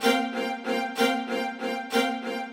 GS_Viols_95-C1.wav